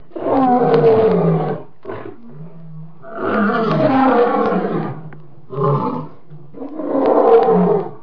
دانلود صدای شیر ماده هنگام غرش کردن از ساعد نیوز با لینک مستقیم و کیفیت بالا
جلوه های صوتی